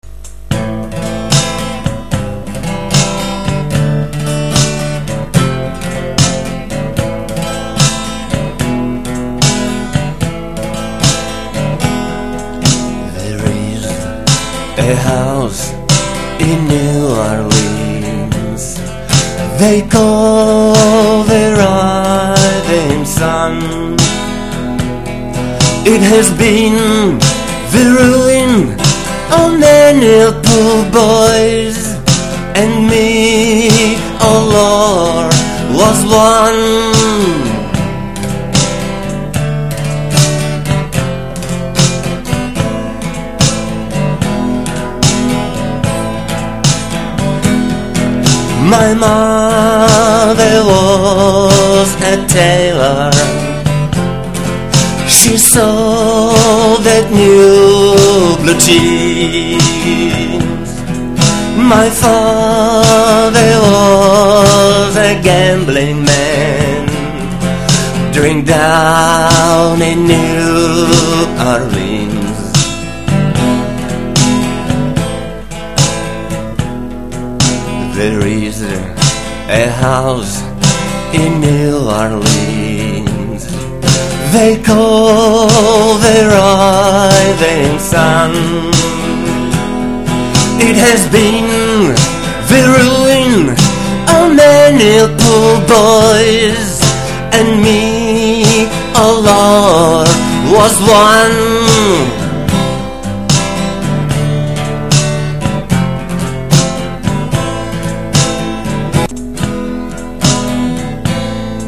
I am singing: